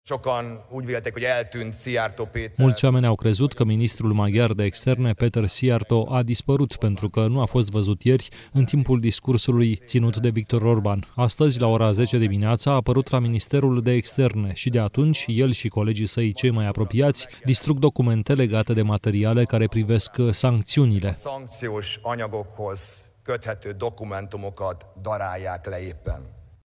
În conferința de presă ținută după victorie, liderul partidului TISZA, Péter Magyar, care ar urma să preia funcția de premier, l-a acuzat pe ministrul de externe din cabinetul Orbán că ar fi distrus documente confidențiale care ar atesta legături strânse cu Rusia.